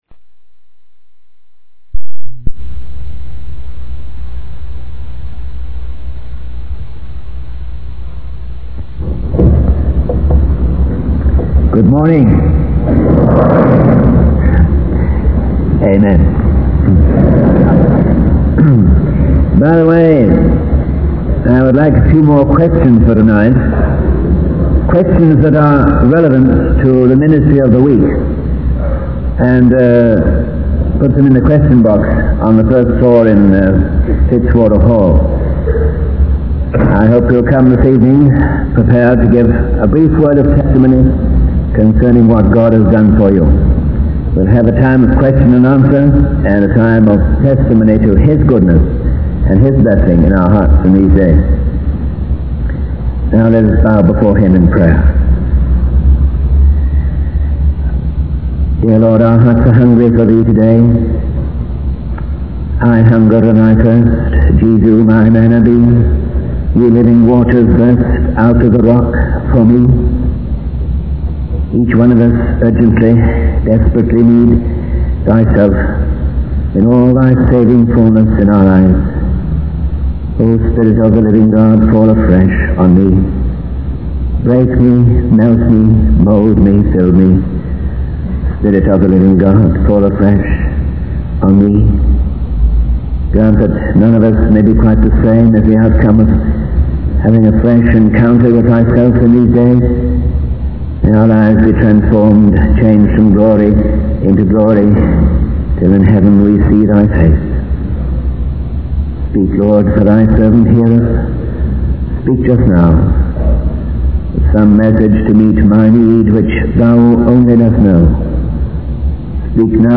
In this sermon, the preacher emphasizes the importance of being a dedicated and serious follower of God. He describes a true believer as someone who is not just superficially engaged with the Bible, but who has been transformed by the power of God.